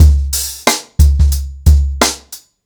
• 90 Bpm Drum Loop Sample C# Key.wav
Free drum loop sample - kick tuned to the C# note. Loudest frequency: 1677Hz
90-bpm-drum-loop-sample-c-sharp-key-qHg.wav